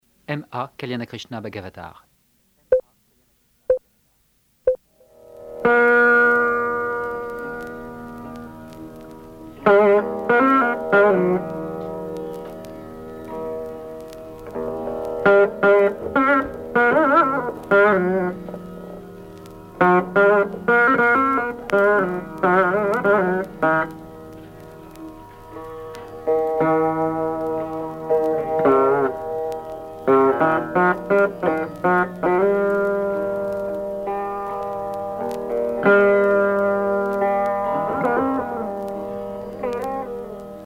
Musique carnatique
Pièce musicale inédite